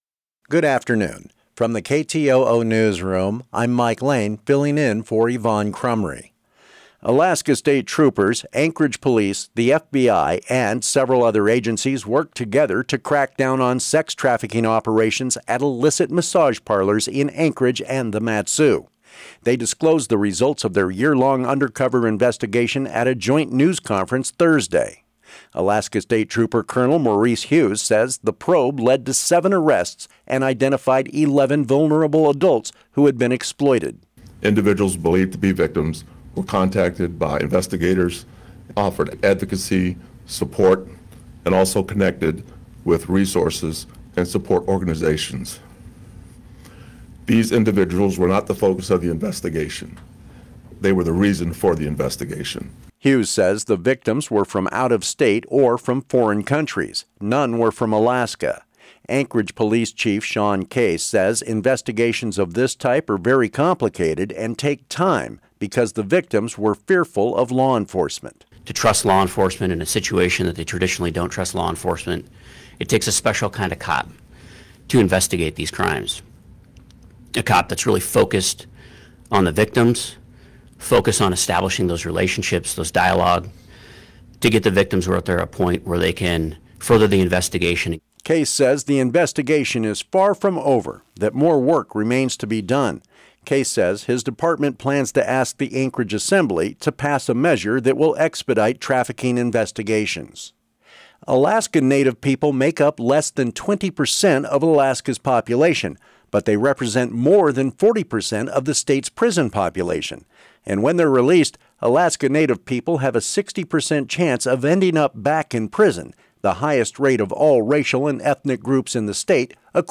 Newscast - Monday, April 20, 2026